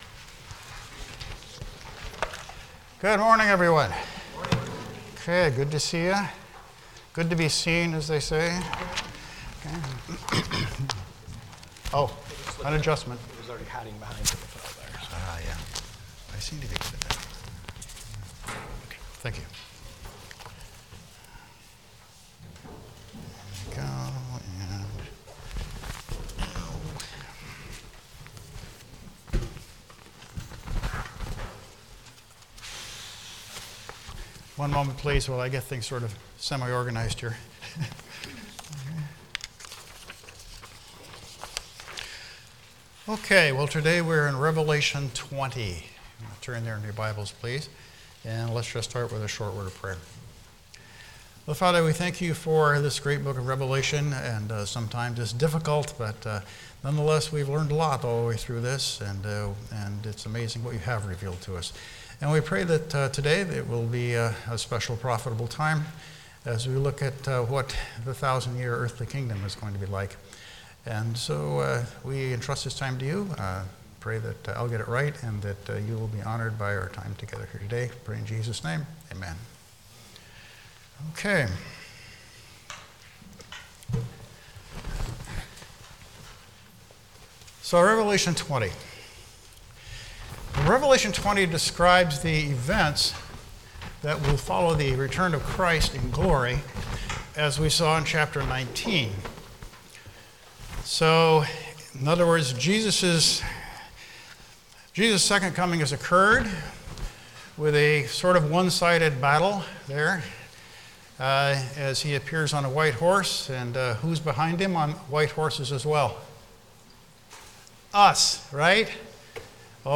Passage: Revelation 20 Service Type: Sunday School